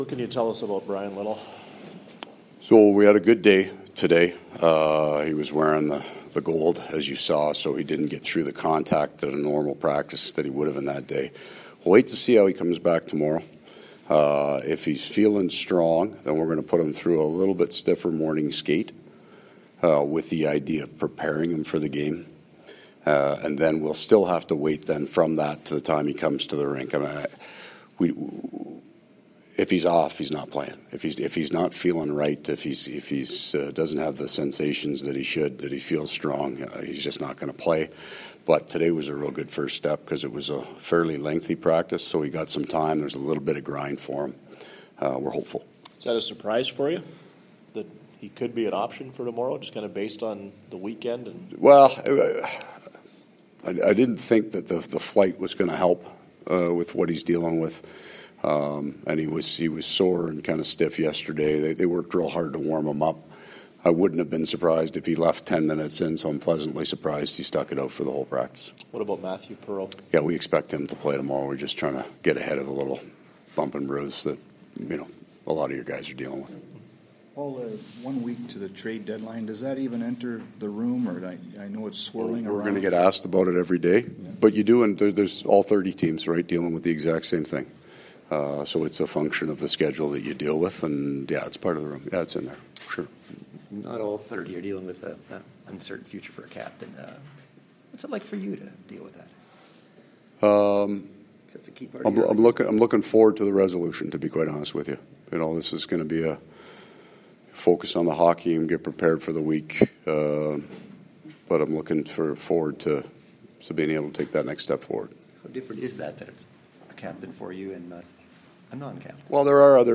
Coach scrum
Coach Maurice chatted with media following the skate at at the MTS IcePlex.